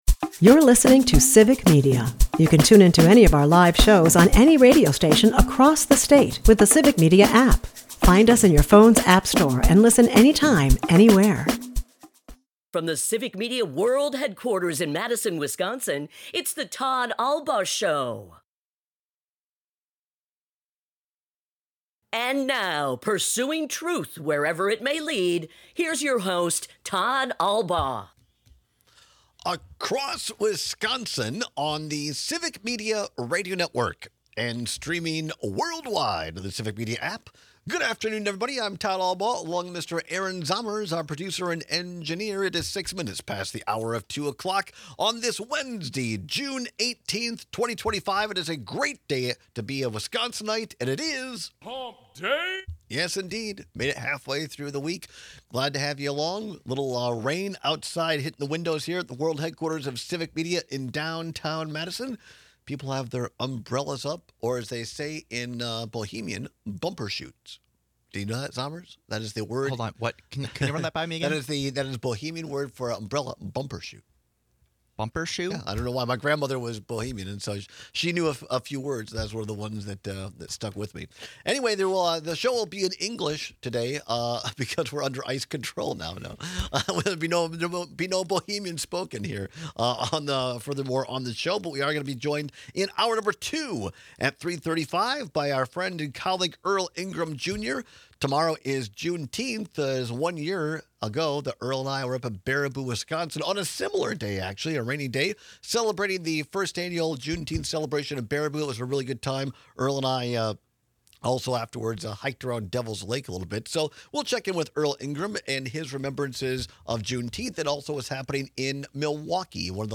This Wednesday, we are joined by our typical midweek guests